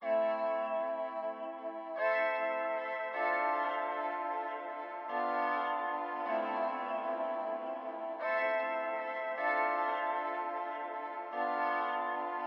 Tag: 128 bpm Cinematic Loops Synth Loops 3.78 MB wav Key : Unknown